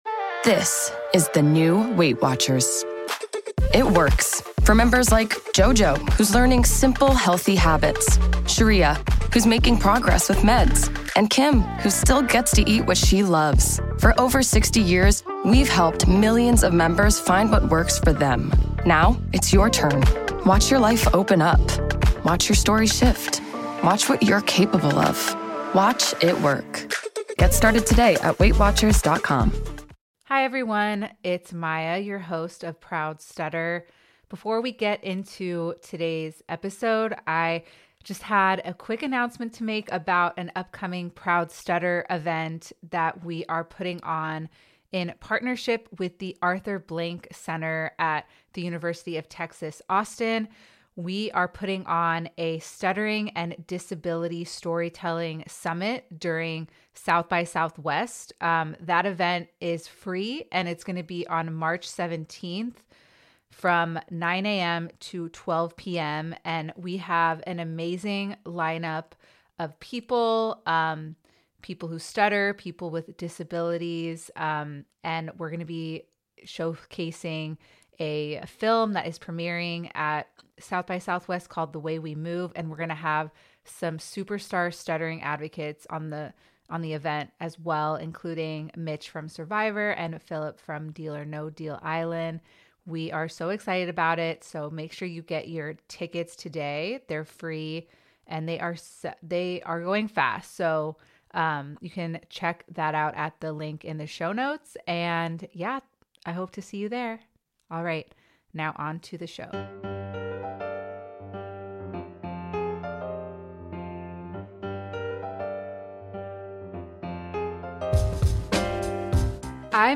The conversation looks at how storytelling can transform difficult experiences into something meaningful.